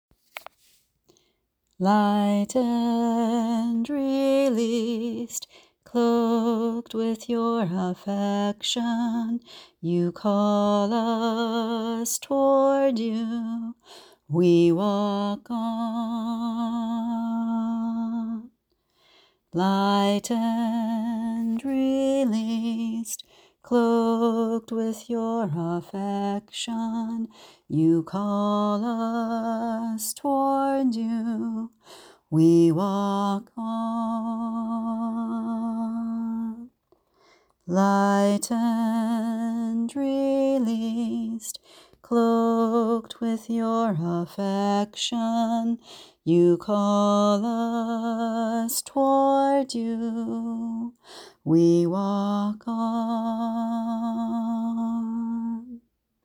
Chant: Lightened, released, cloaked with Your affection, You call us toward You.